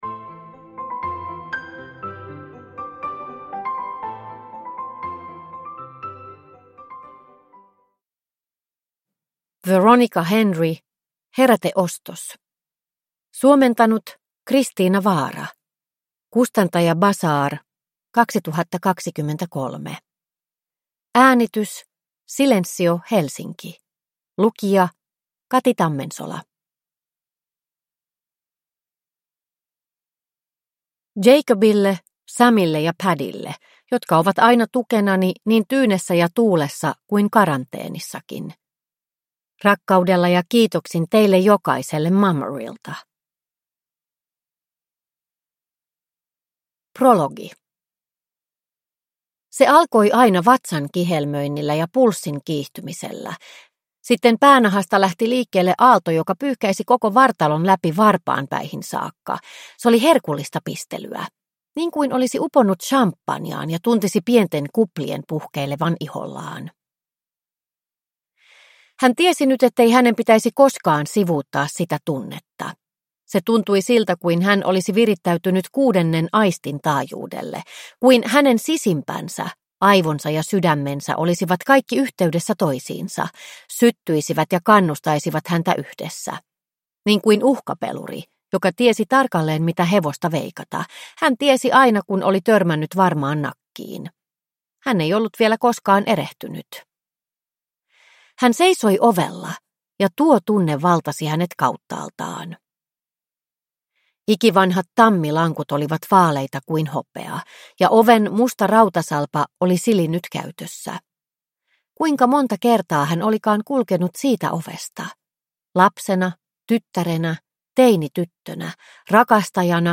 Heräteostos – Ljudbok – Laddas ner